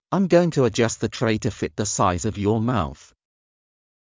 ｱｲﾑ ｺﾞｰｲﾝｸﾞ ﾄｩ ｱｼﾞｬｽﾄ ｻﾞ ﾄﾚｲ ﾄｩ ﾌｨｯﾄ ｻﾞ ｻｲｽﾞ ｵﾌﾞ ﾕｱ ﾏｳｽ